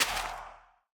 Minecraft Version Minecraft Version snapshot Latest Release | Latest Snapshot snapshot / assets / minecraft / sounds / block / soul_sand / break6.ogg Compare With Compare With Latest Release | Latest Snapshot